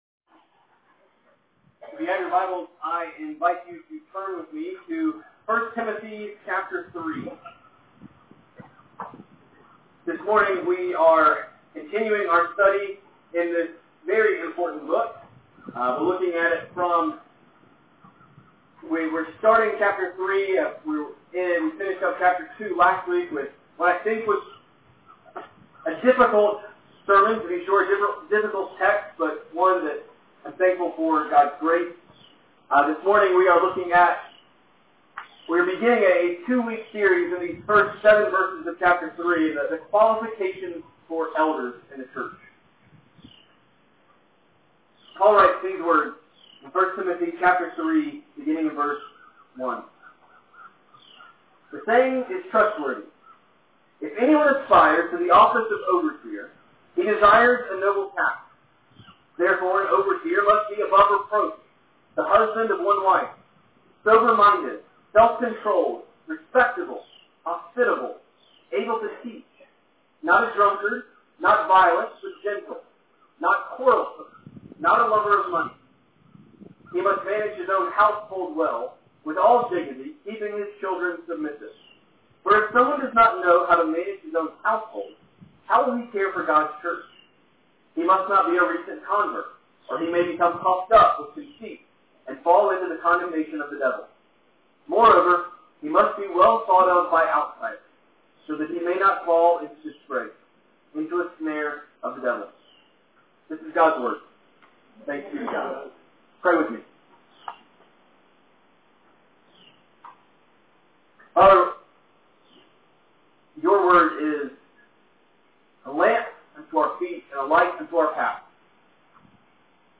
1 Timothy 3:1-7 Outdoor service – Audio problems